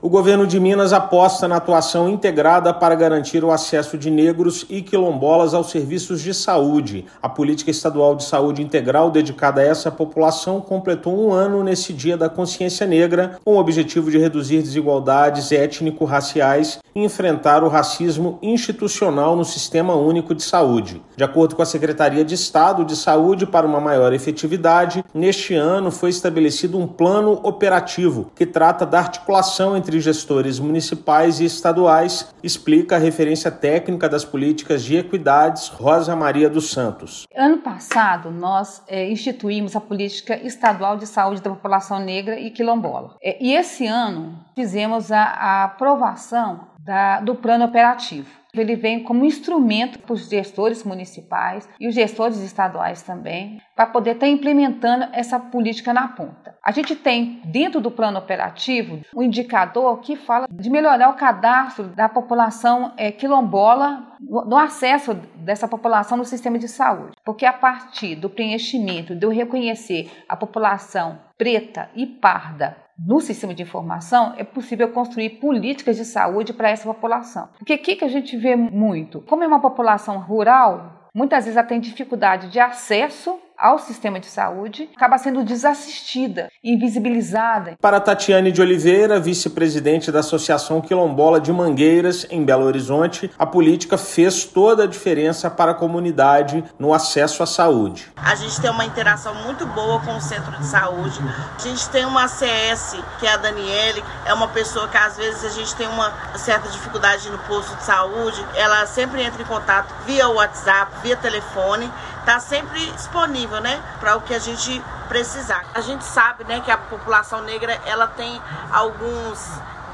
[RÁDIO] Governo aposta em atuação integrada para garantir o acesso de negros e quilombolas aos serviços de saúde
Articulação entre atores municipais e estaduais visa melhorar qualidade de vida e atendimento para população. Ouça matéria de rádio.